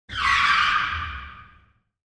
drift-sound-effect_xwZFLY3.mp3